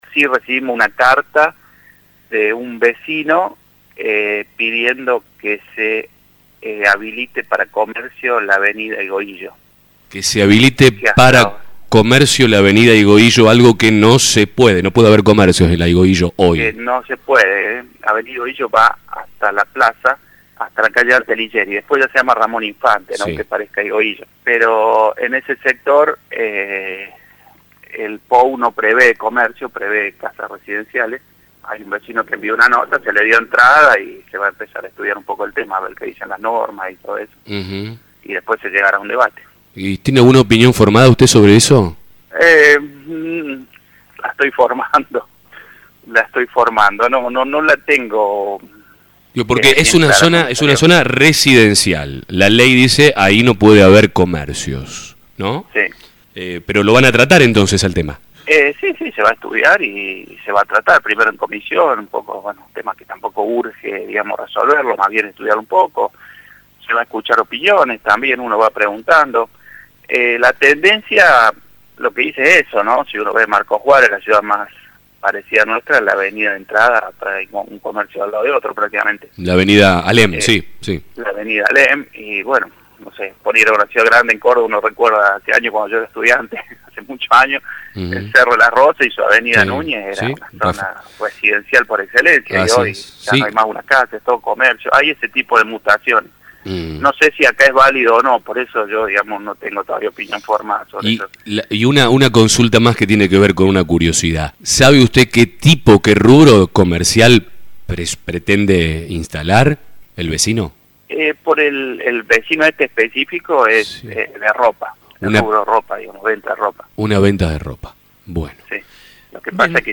El concejal Alejandro Rosso, en diálogo con La Mañana, explicó que el pedido será analizado por el cuerpo